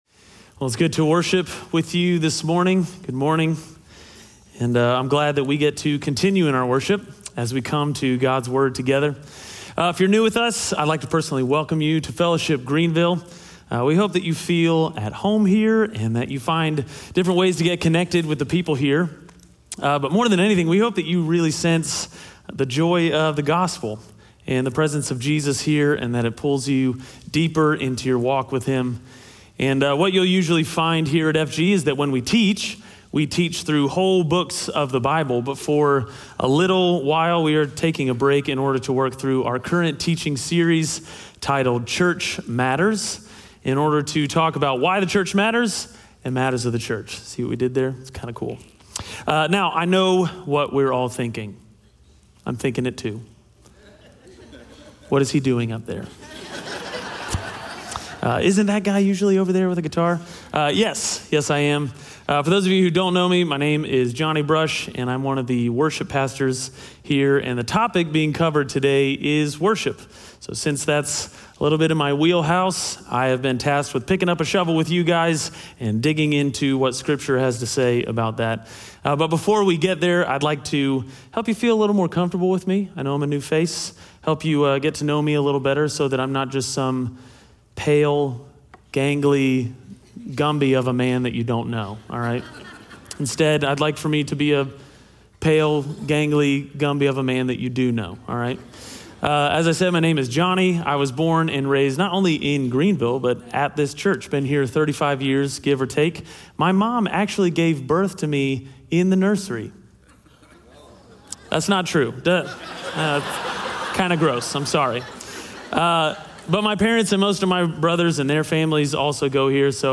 Psalm 95 Audio Sermon Notes (PDF) Ask a Question SERMON SUMMARY Gathering with God’s people for the purpose of worshiping Him isn’t just a good idea, it’s a God idea.